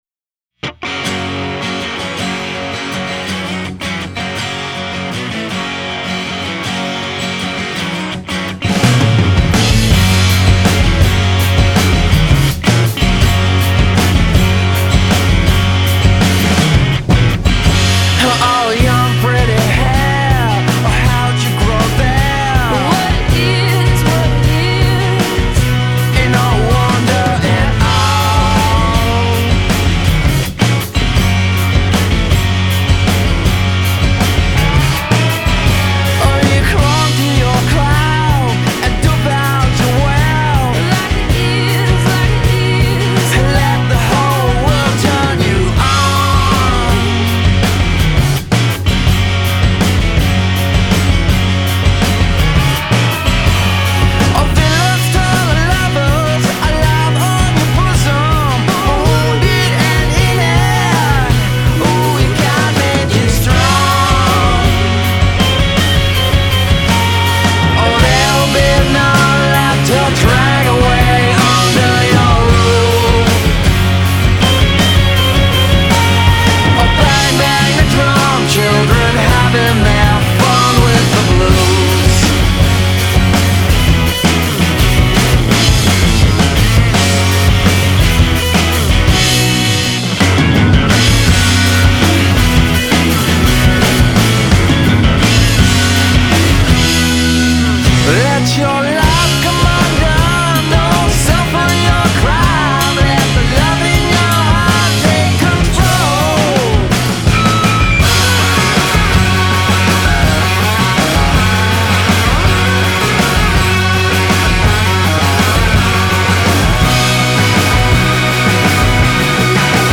bold stoner psych sound